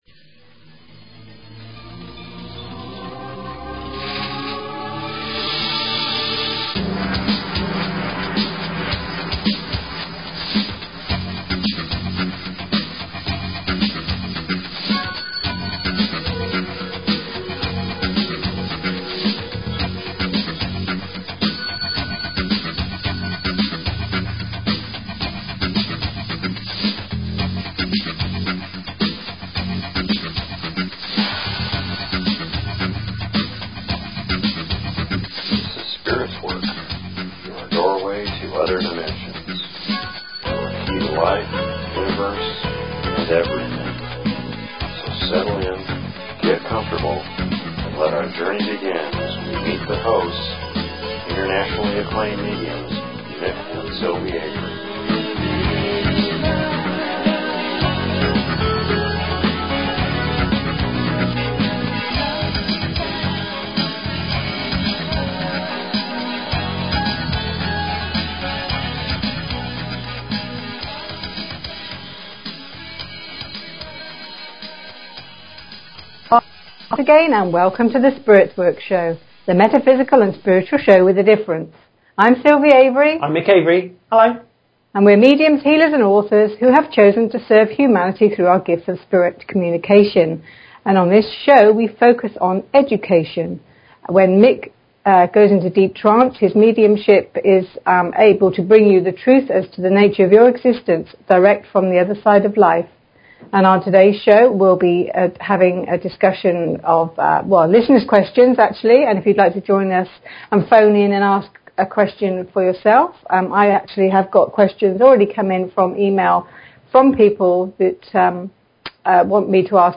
Talk Show Episode, Audio Podcast, Spirits_Work and Courtesy of BBS Radio on , show guests , about , categorized as